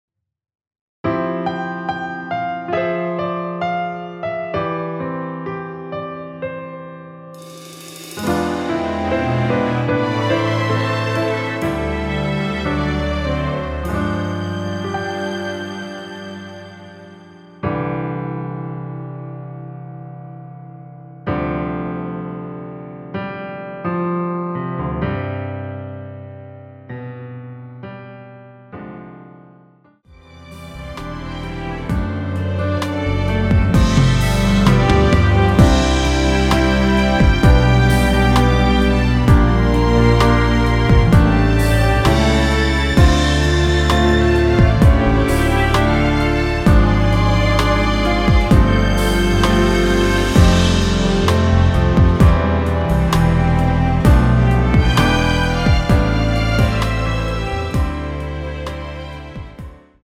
원키에서(-2)내린 MR 입니다.
앞부분30초, 뒷부분30초씩 편집해서 올려 드리고 있습니다.
중간에 음이 끈어지고 다시 나오는 이유는